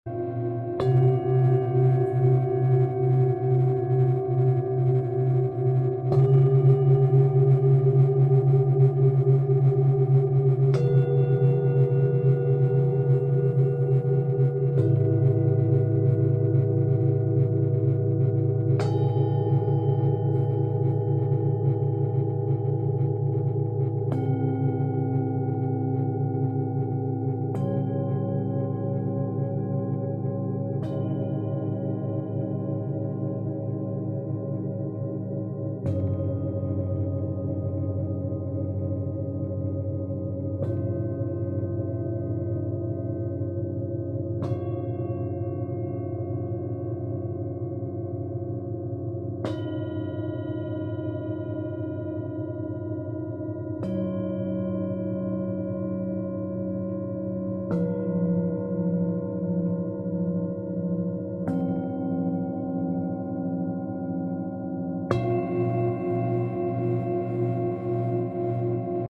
Sound Bath Recording